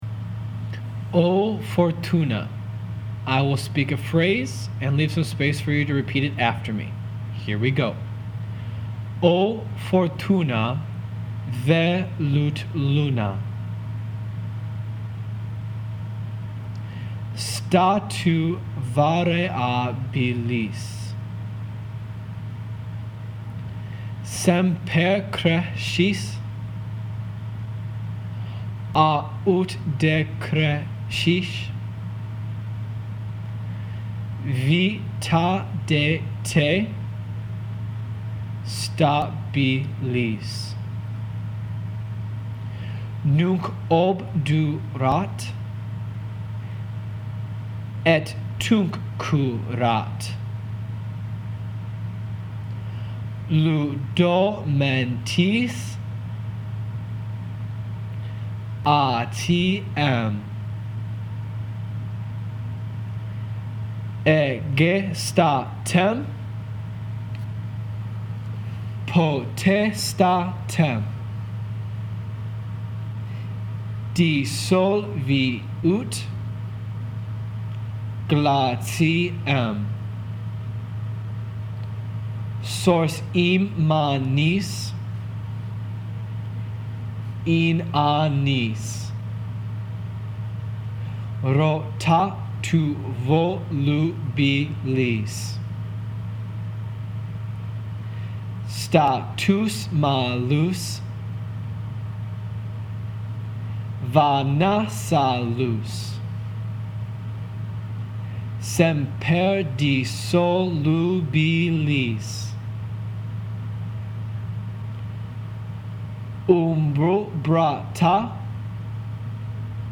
Here are two pronunciation guides to help you practice these songs.
O-Fortuna-pronunciation.mp3